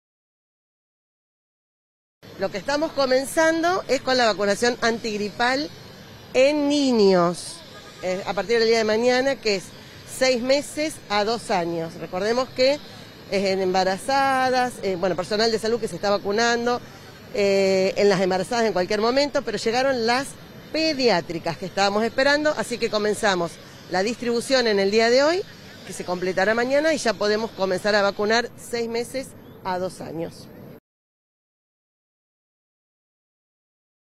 Sonia Martorano, ministra de Salud de la provincia